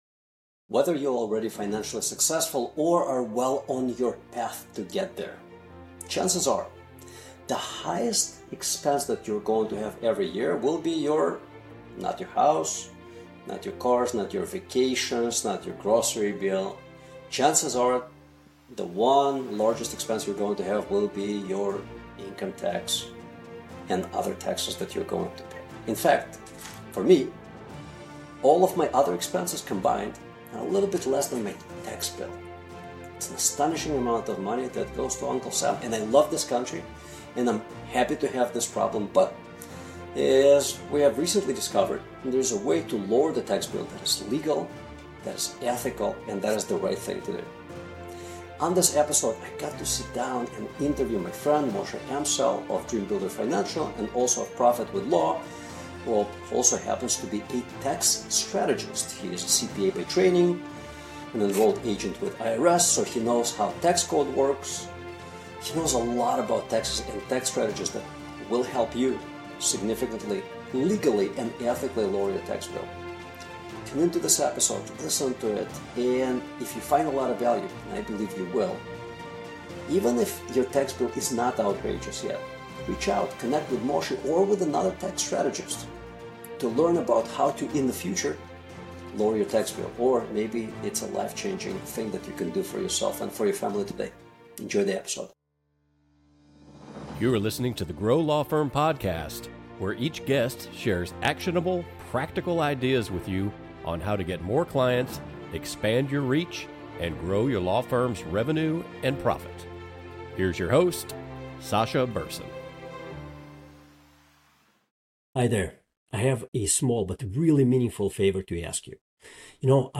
The GLF Podcast brings you insightful conversations with leaders in the legal business field, discussing practical ideas that will help you grow your law firm, expand your reach, generate more revenue, and make your law firm more valuable.